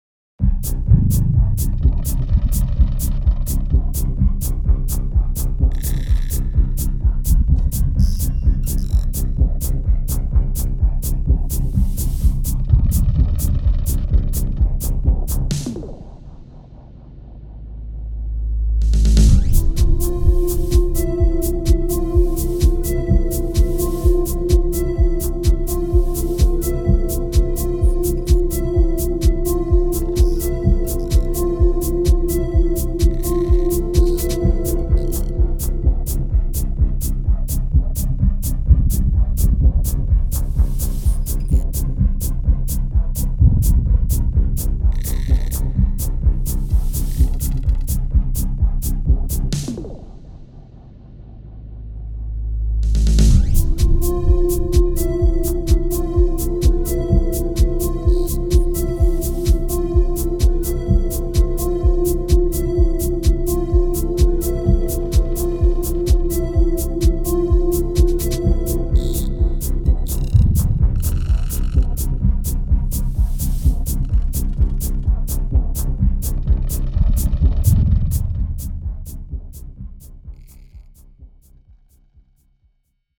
Here is a simple performance I made tonight. It plays pattern A01-8 times, then A02-2 times, then A03-8 times and loops (it goes back to A01 so the logic starts over).